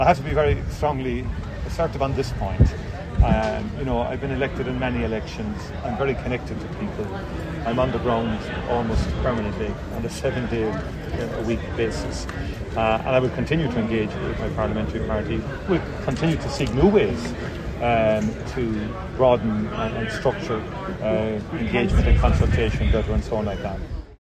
Speaking to the media in Dublin yesterday, Micheál Martin said he was elected as Fianna Fáil leader just a year and a half ago.